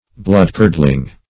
bloodcurdling \blood"curd*ling\ adj.